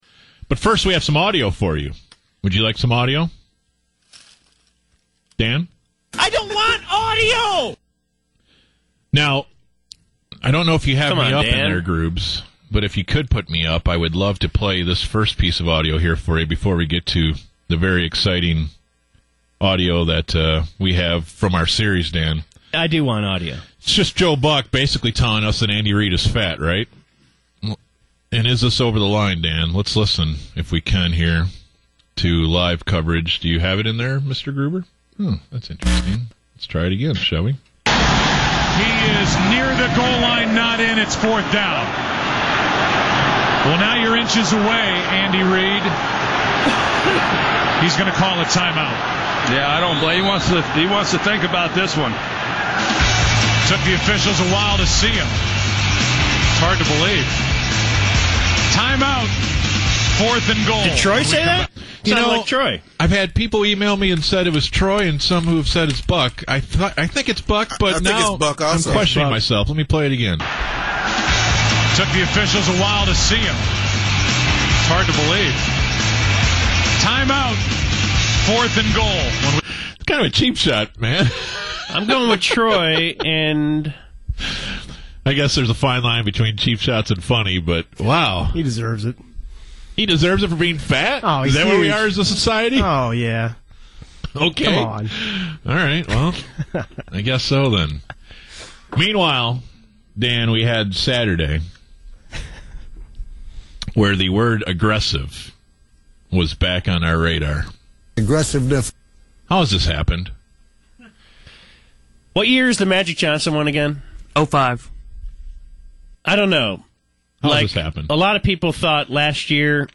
You would think the word “Aggressive” (or “Aggressiveness”) wouldn’t be that hard to pronounce, but people keep getting it wrong.